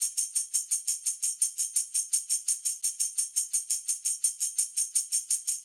Index of /musicradar/sampled-funk-soul-samples/85bpm/Beats
SSF_TambProc1_85-03.wav